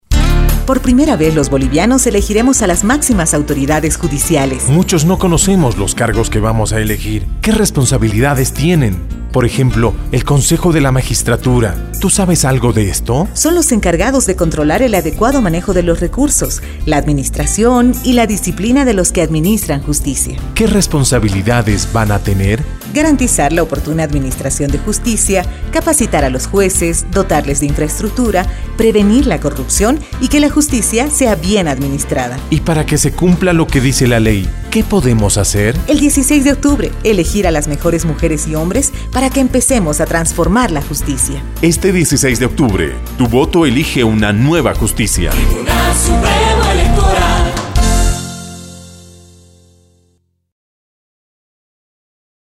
Cuñas